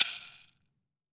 WOOD SHAKER1.WAV